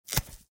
Звуки каната, троса
Звук рвущейся веревки или каната